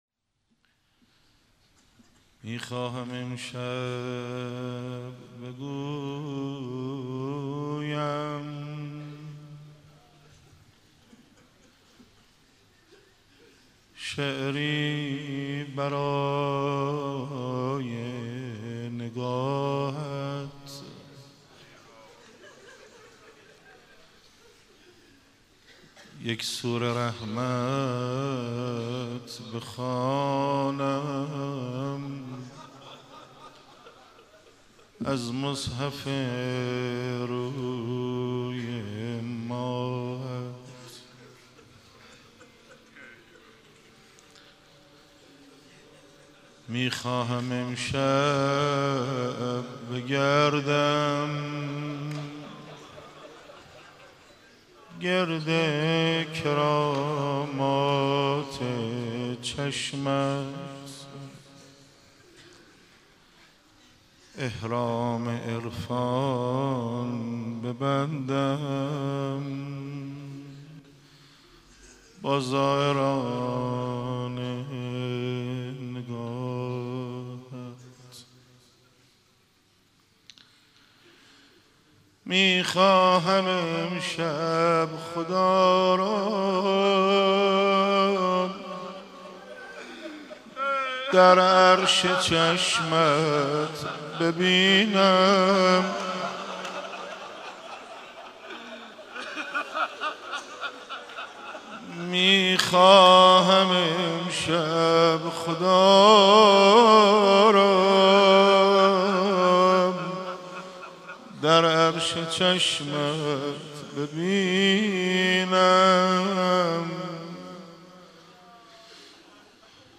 مداحی حاج حسن خلج بمناسبت شهادت امام هادی (ع)
دانلود حاج حسن خلج شهادت امام هادی ع روضه وارث اخبار مرتبط انقلاب درونی مناجات خوان معروف تهران نماز عید فطر چگونه است؟